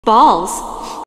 Play Barbie Balls (reverb) - SoundBoardGuy
Play, download and share Barbie balls (reverb) original sound button!!!!
barbie-balls-reverb.mp3